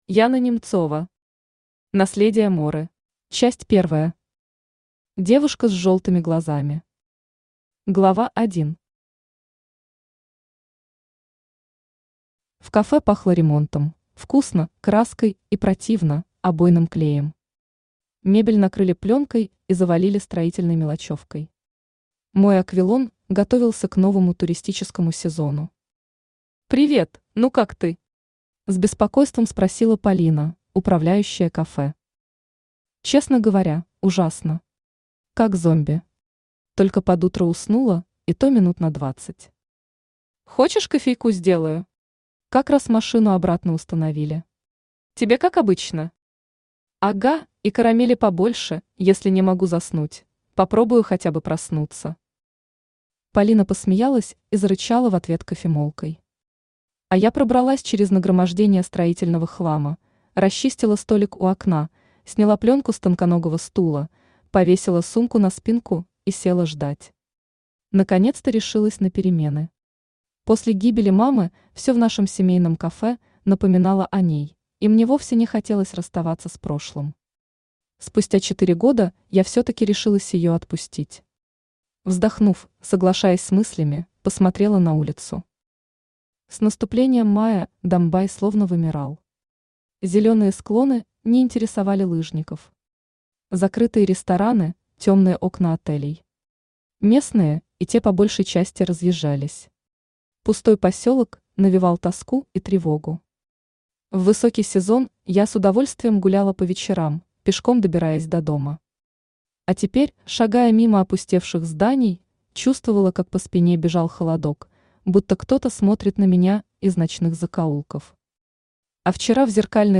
Аудиокнига Наследие Моры | Библиотека аудиокниг
Aудиокнига Наследие Моры Автор Яна Александровна Немцова Читает аудиокнигу Авточтец ЛитРес.